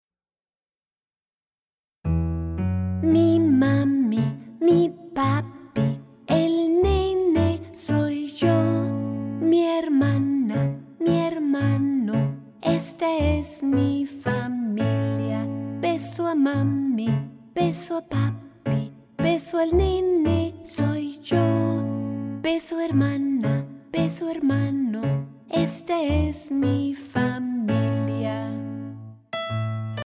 A Song for Learning Spanish